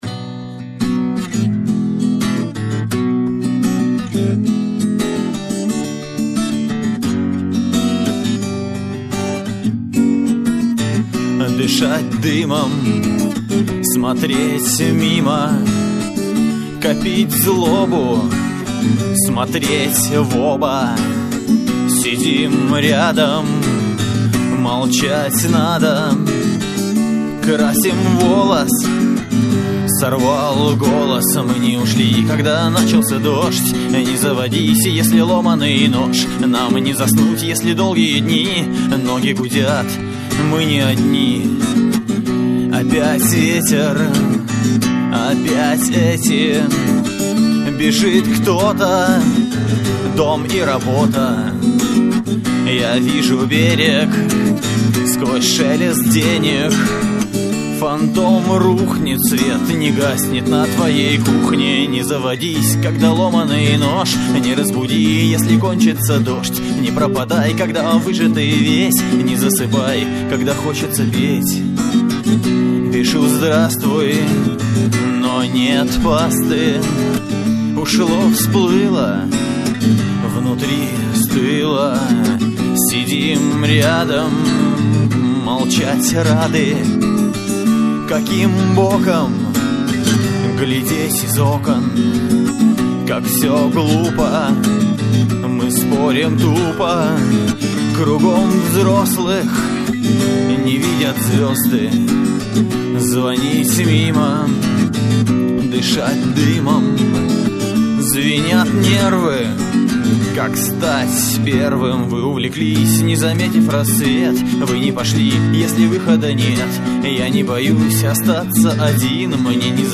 112 kbps, stereo, studio sound. audio live